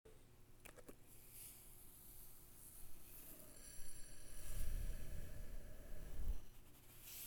Salt Pour
Item from What Glass Shards Are Called: An audio sample of salt being poured onto a plate to imitate the sound of glass shards being poured.
with a Zoom H4n Handy Recorder